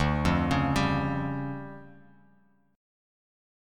DmM7bb5 chord